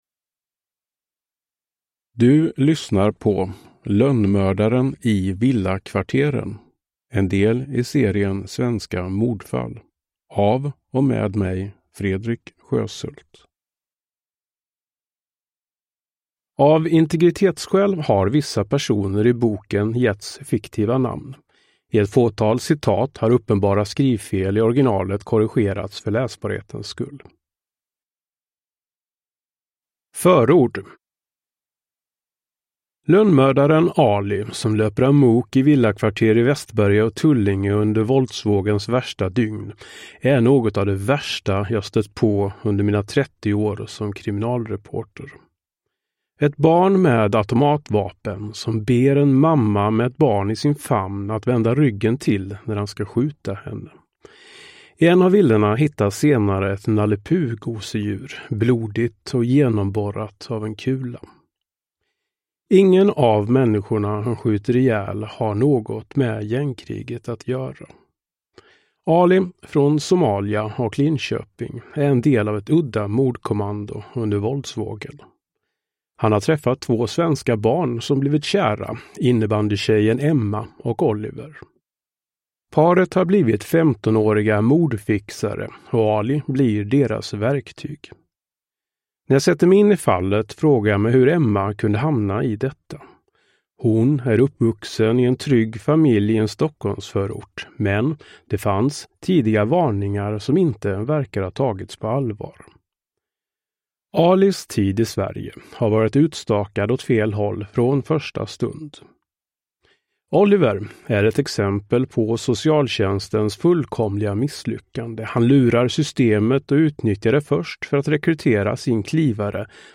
Svenska mordfall: Lönnmördaren i villakvarteren (ljudbok) av Fredrik Sjöshult